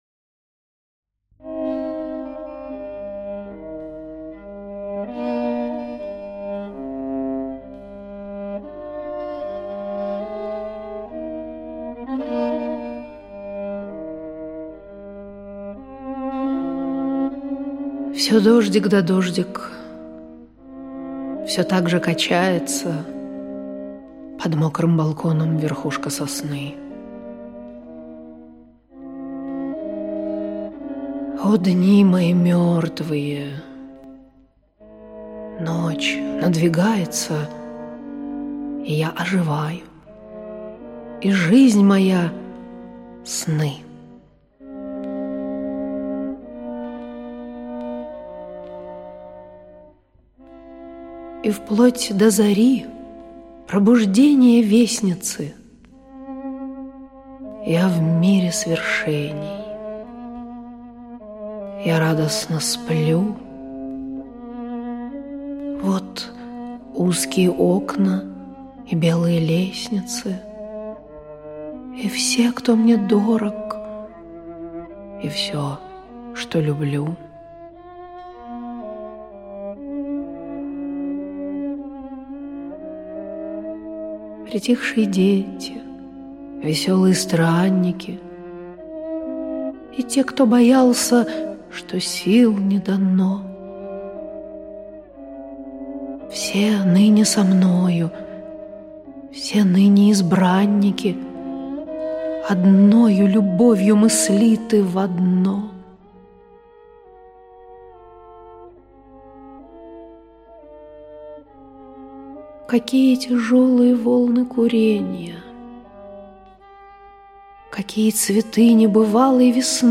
Аудиокнига Сно-Видения | Библиотека аудиокниг
Прослушать и бесплатно скачать фрагмент аудиокниги